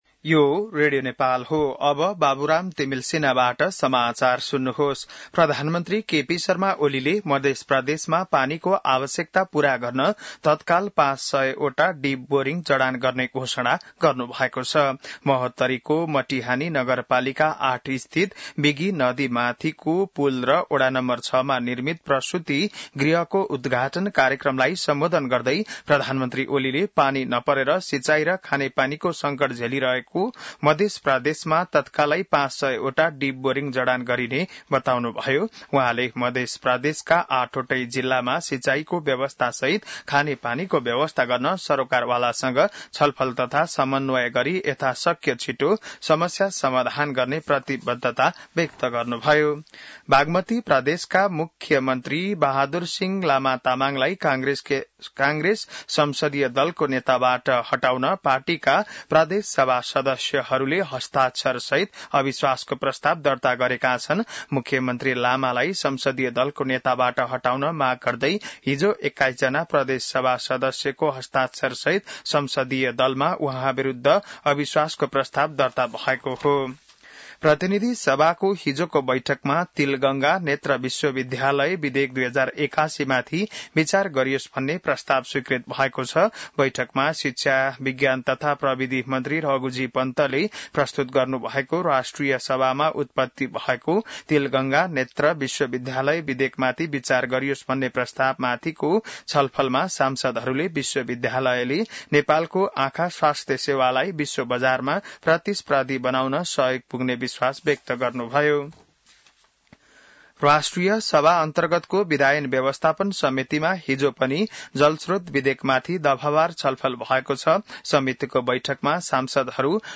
बिहान १० बजेको नेपाली समाचार : १८ पुष , २०२६